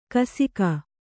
♪ kasika